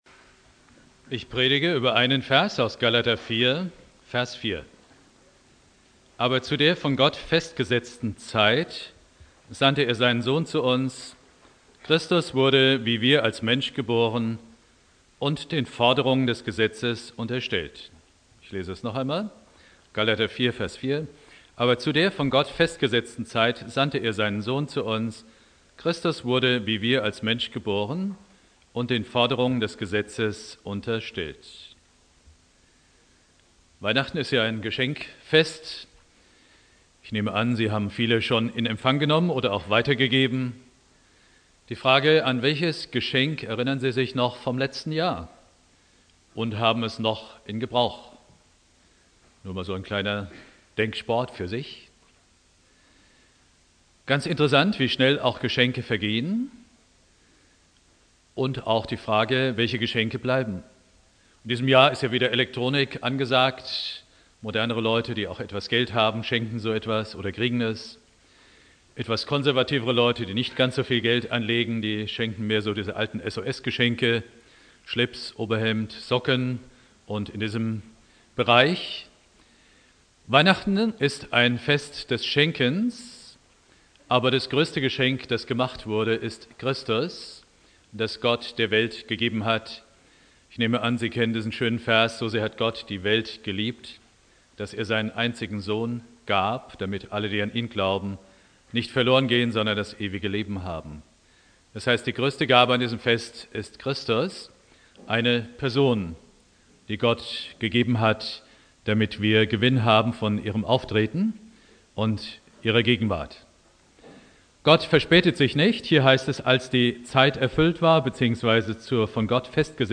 Predigt
Heiligabend (18 Uhr) Bibeltext: Galater 4,4 Dauer